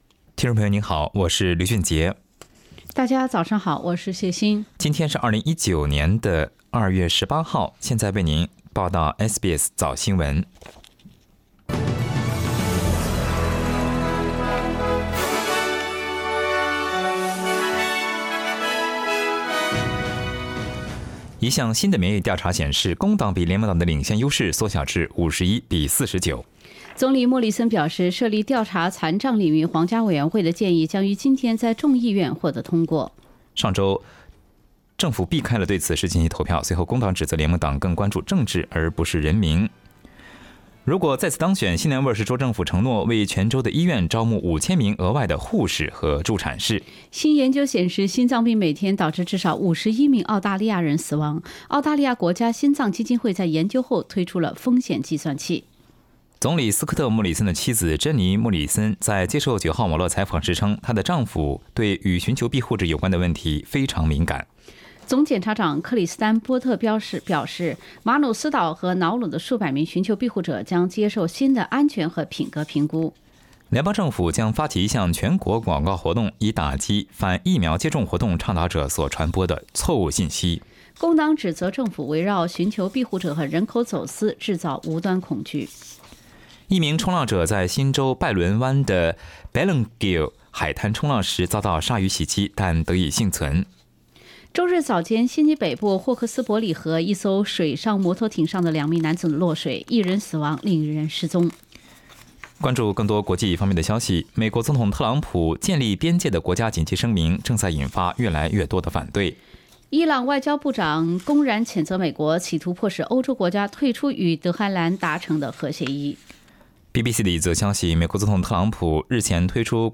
SBS 早新聞 （02月18日）